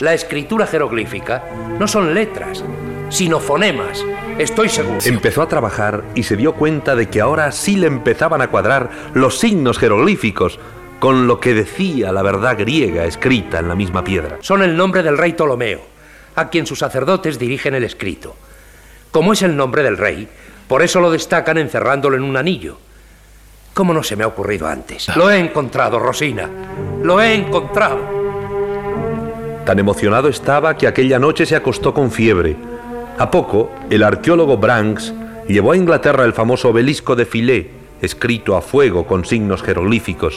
Publicitat i equip del programa.
Divulgació